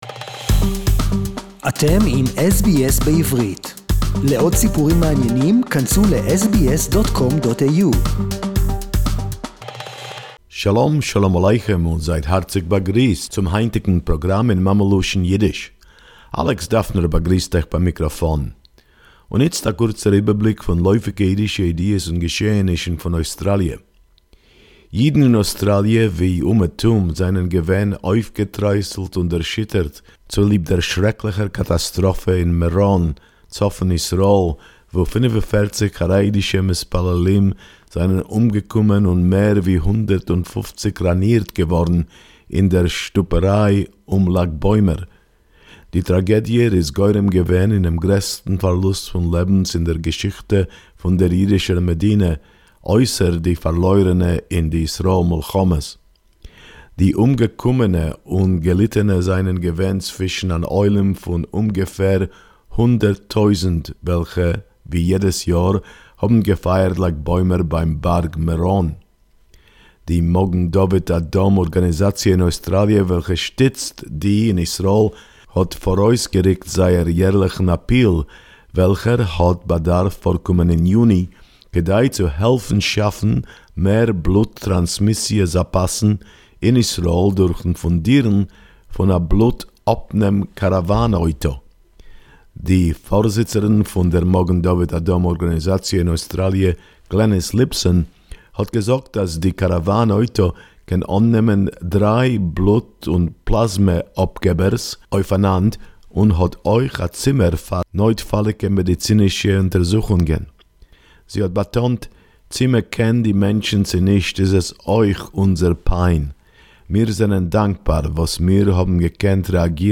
PM Scott Morrison, praised the Australian Jewish community SBS Yiddish report